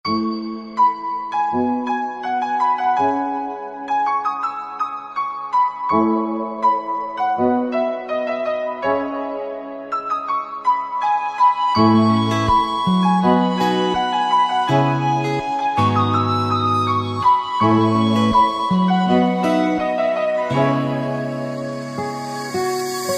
emotional background music